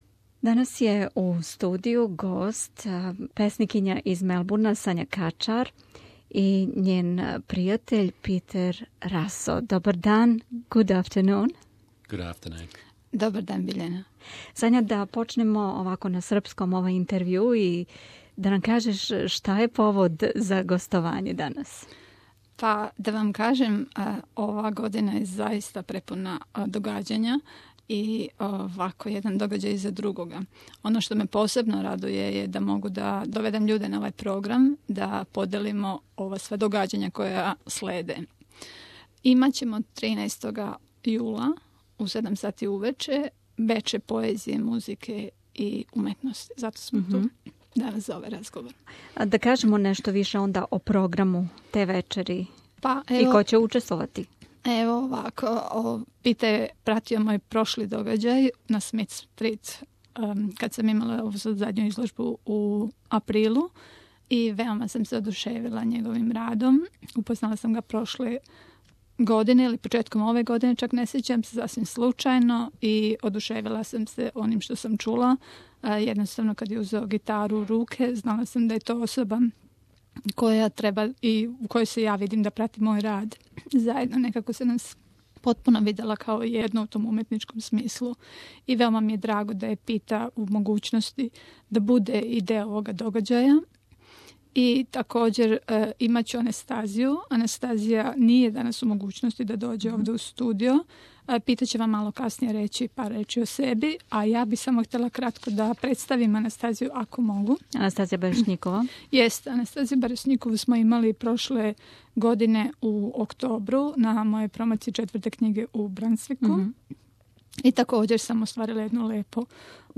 visited our Melbourne studio on Wednesday